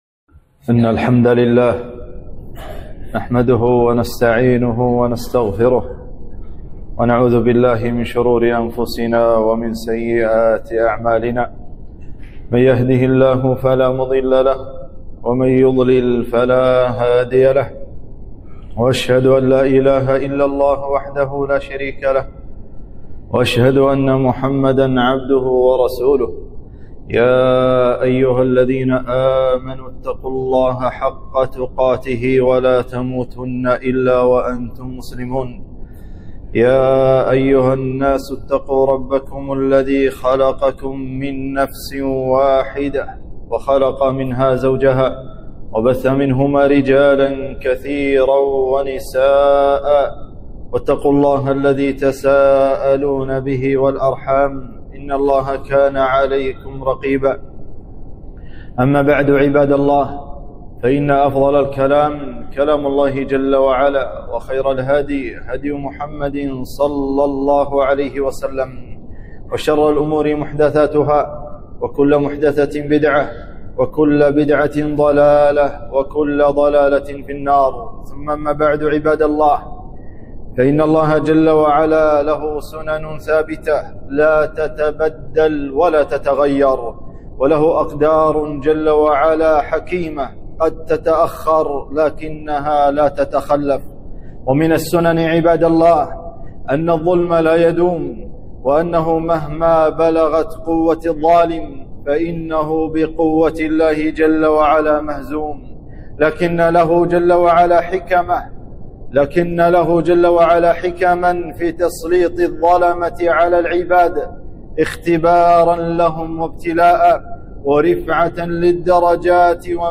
خطبة - نصر الله قريب